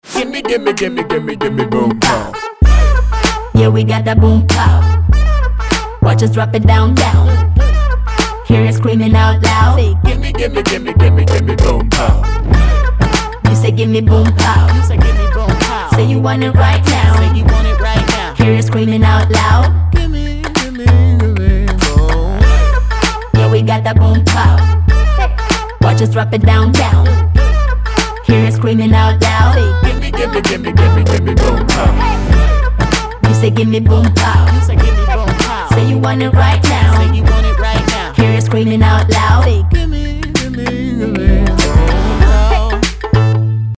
• Качество: 320, Stereo
поп
dance
Saxophone